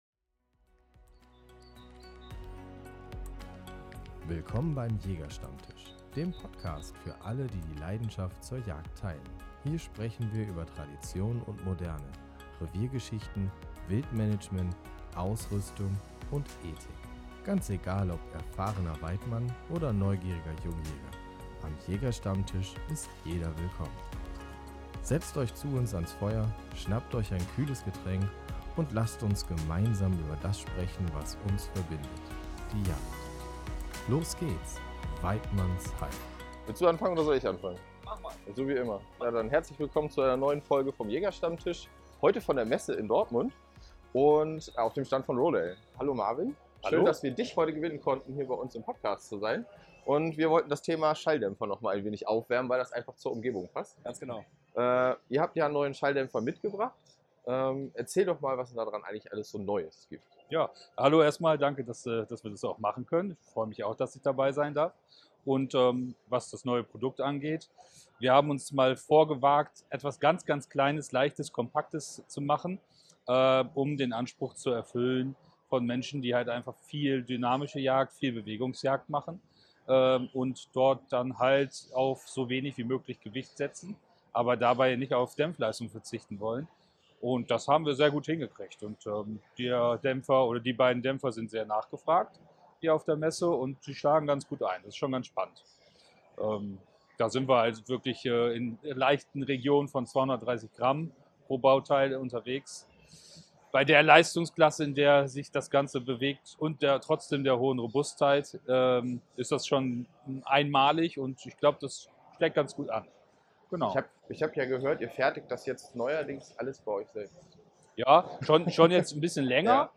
Eine Folge direkt von der Messe, nah an der Praxis und mitten aus der Jagd.